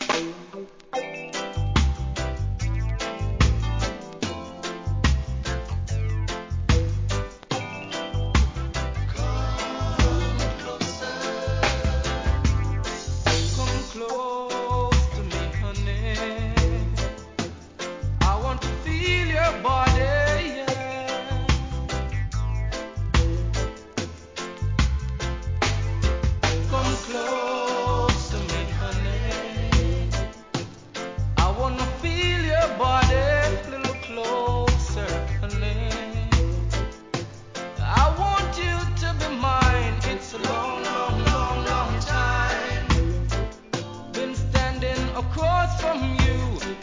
REGGAE
SWEET VOCAL!!